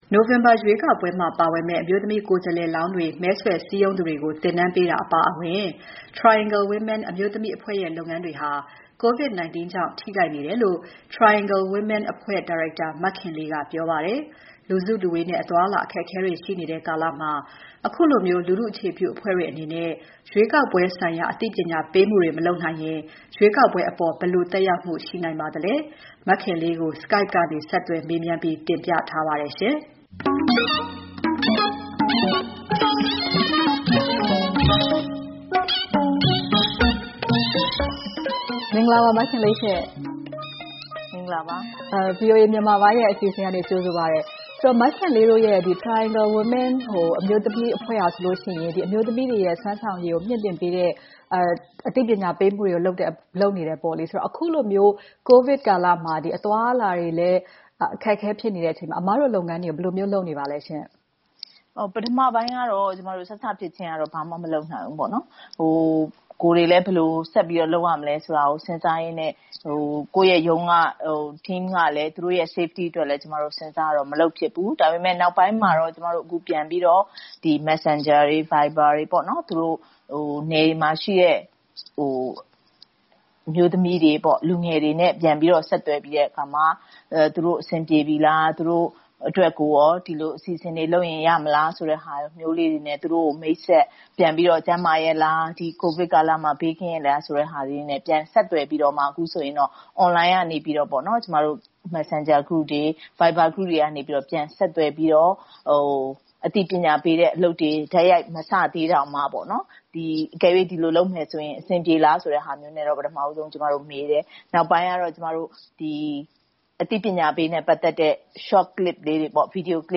Skype ကနေ ဆက်သွယ်မေးမြန်းထားပါတယ်။